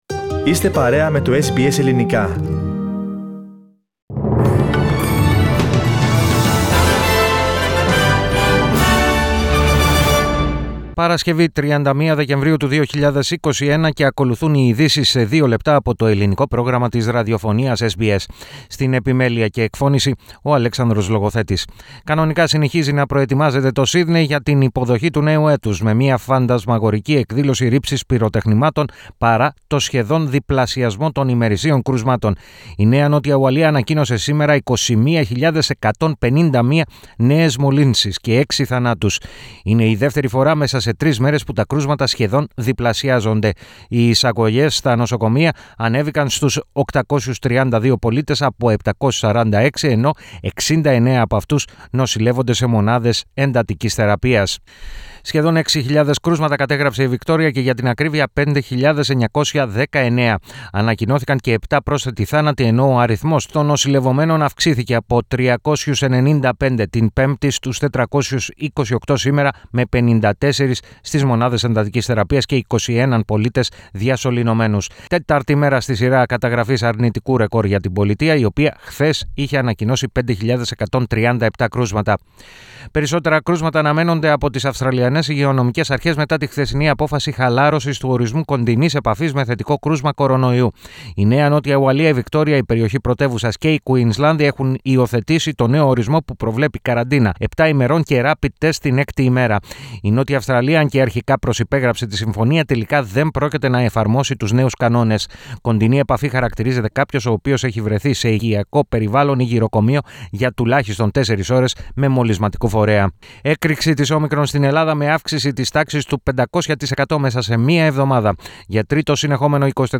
News in brief 31.12.21